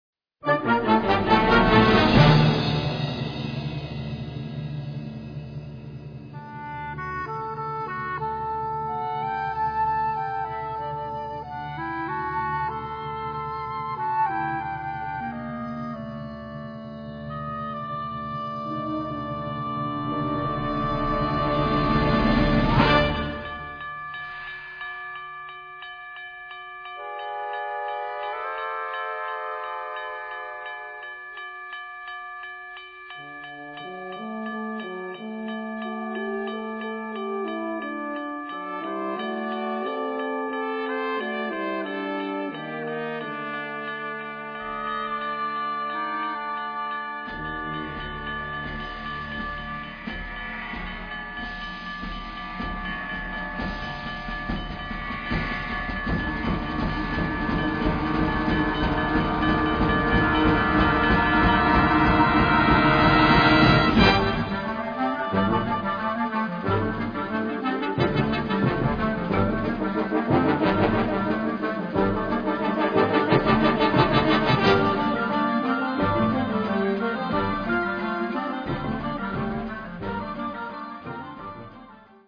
Categoria Concert/wind/brass band
Instrumentation Ha (orchestra di strumenti a faito)
Gli ascoltatori sono sopraffatti dai suoni vivaci.
-Cym (Crash, Hi-Hat, Suspended)
-Gng (Tam-Tam)
-Train Whistle